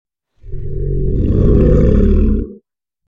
Lion Growl Téléchargement d'Effet Sonore
Lion Growl Bouton sonore